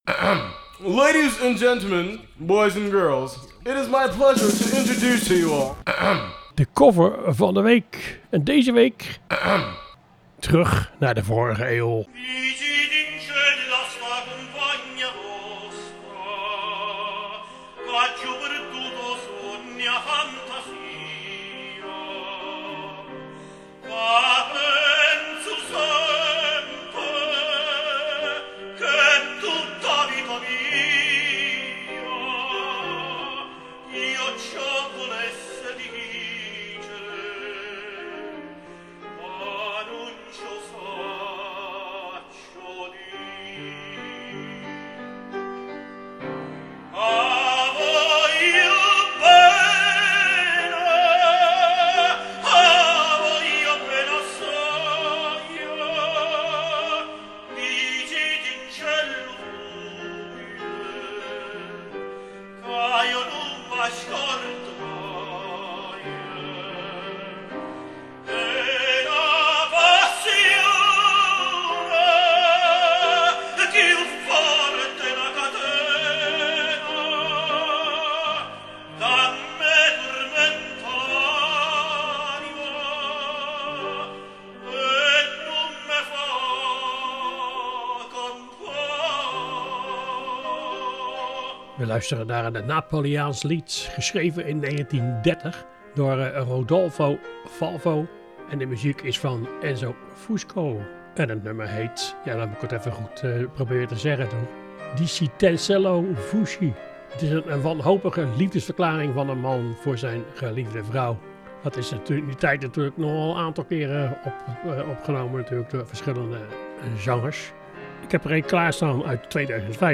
in een modern jasje gestoken